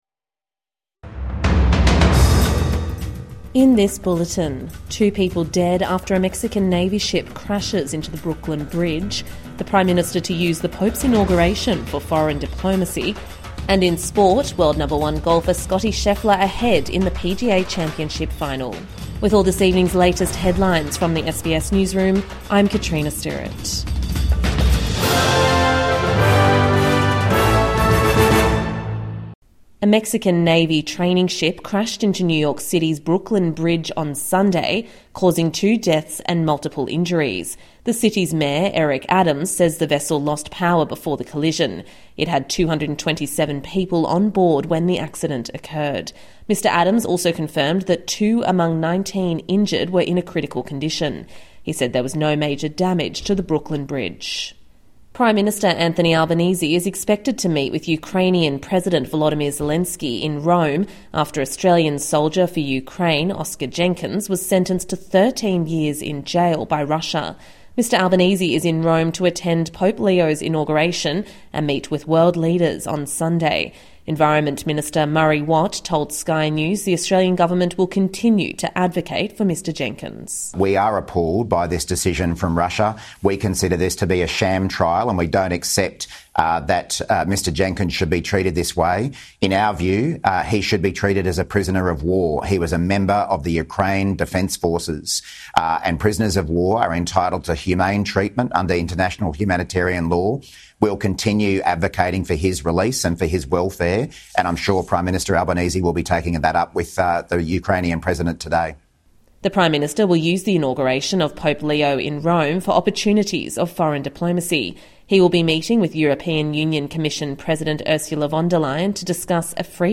Khutbah